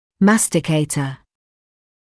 [‘mæstɪkeɪtə][‘мэстикэйтэ]жевательный